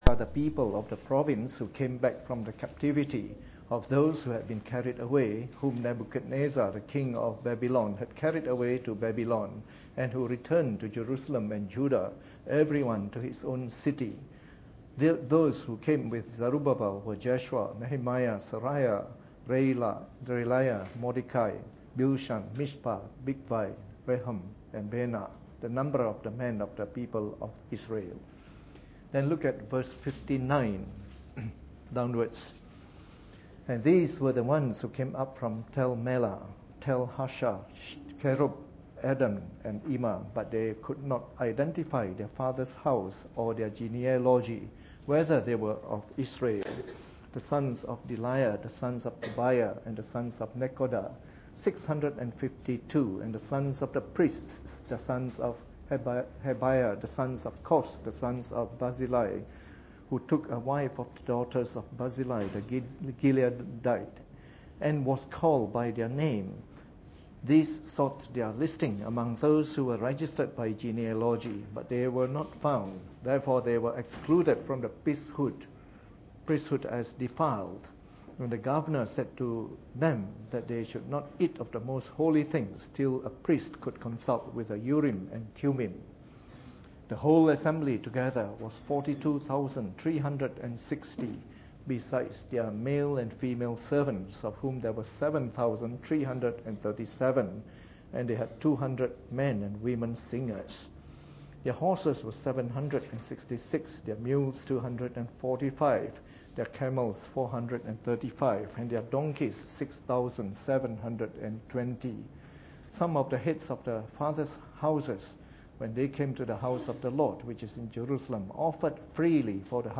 Preached on the 22nd of January 2014 during the Bible Study, from our new series of talks on the Book of Ezra.